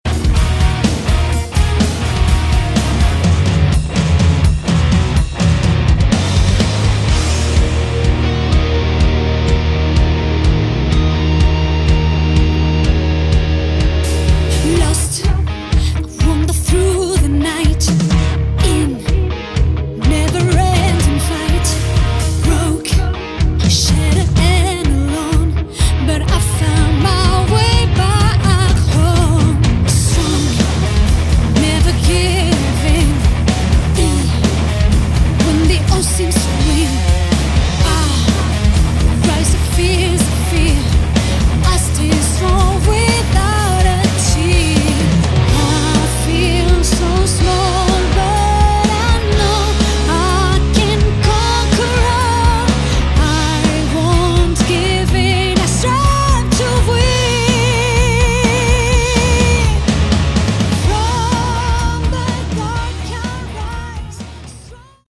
Category: Melodic Rock
vocals
guitar
drums
bass